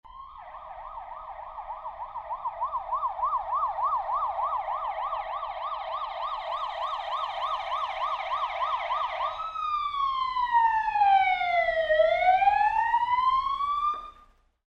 Police Siren Page 16 Sound Button - Free Download & Play